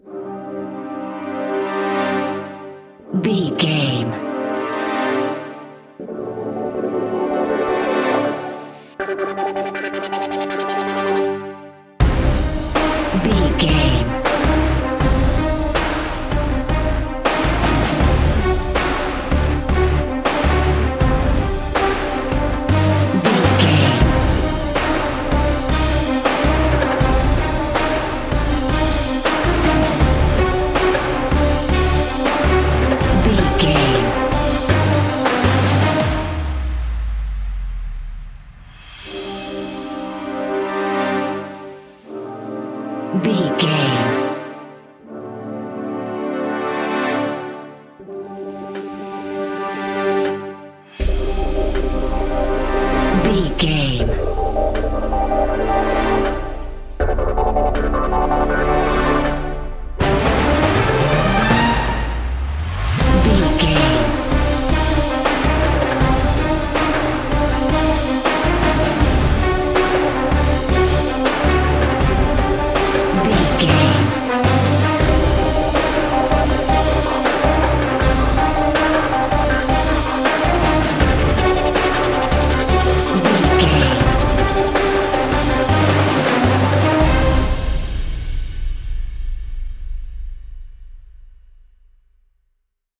Epic / Action
Fast paced
In-crescendo
Uplifting
Aeolian/Minor
DOES THIS CLIP CONTAINS LYRICS OR HUMAN VOICE?